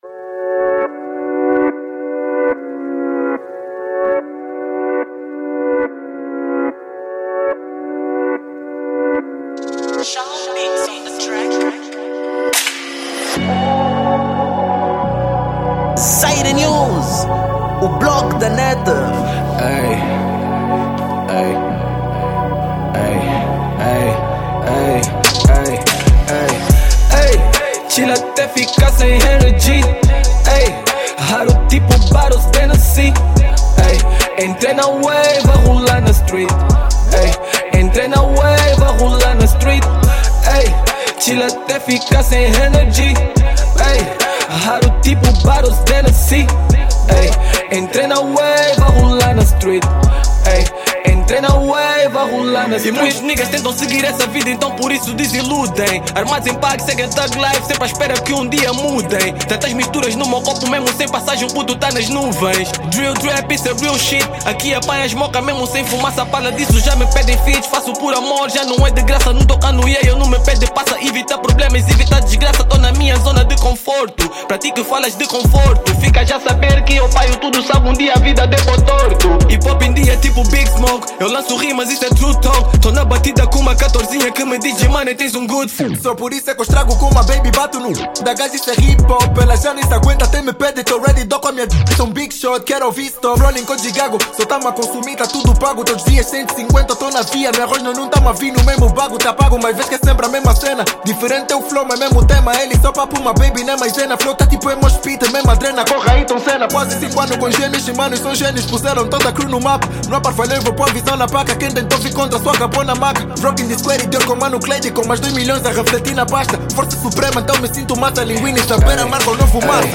Género: Hip-Hop / Rap Formato